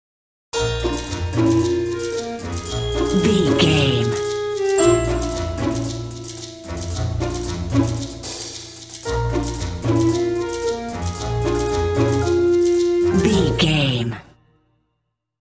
Uplifting
Lydian
B♭
flute
oboe
strings
orchestra
cello
double bass
percussion